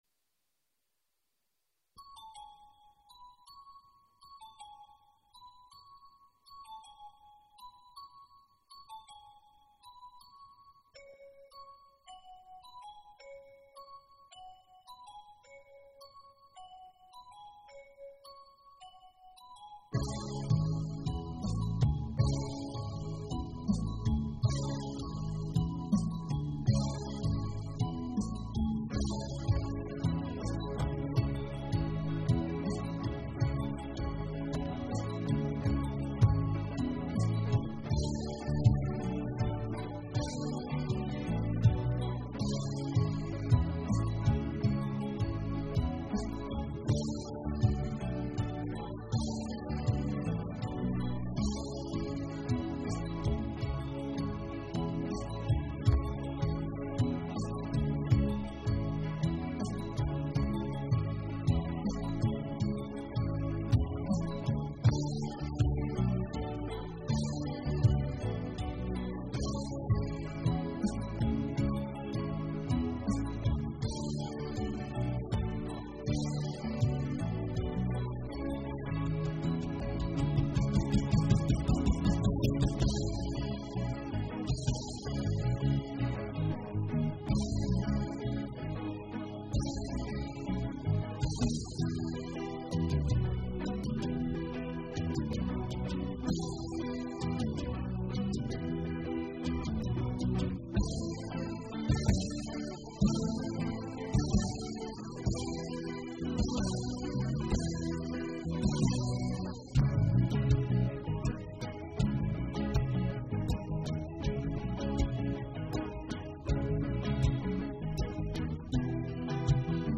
die Studioband (1995-1997)